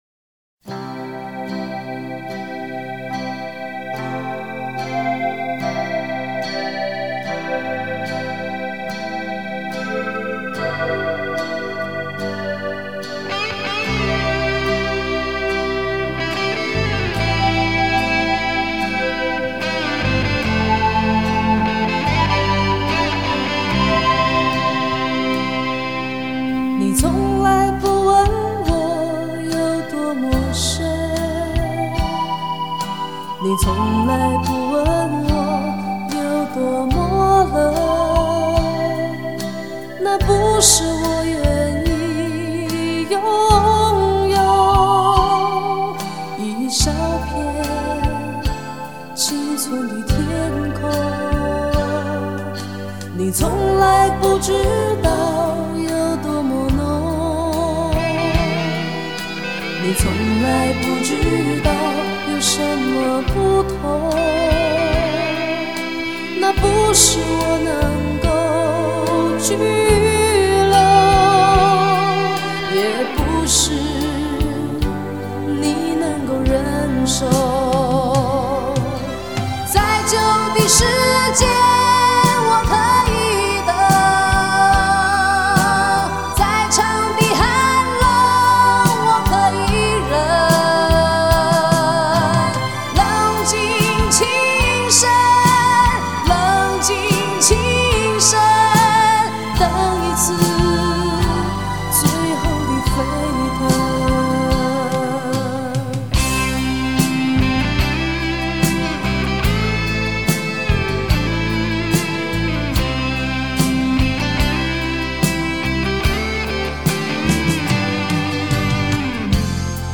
中国台湾女歌手